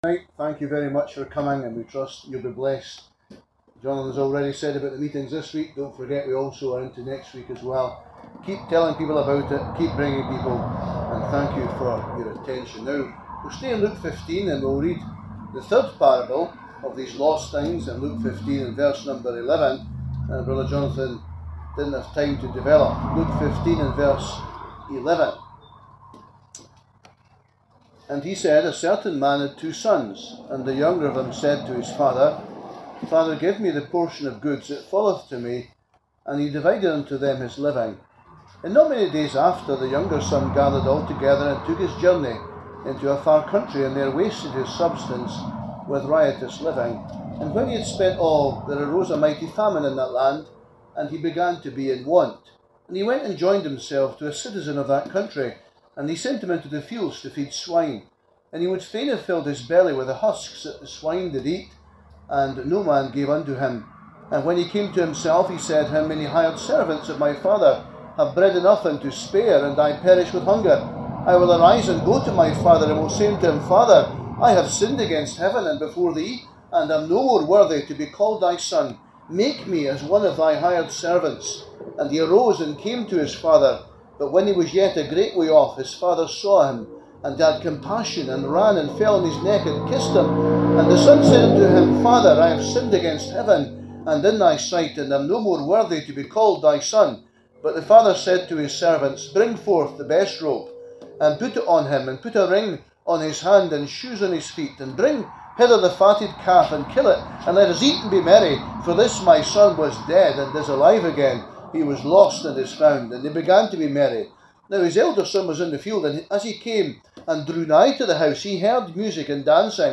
This is a very passionately preached gospel.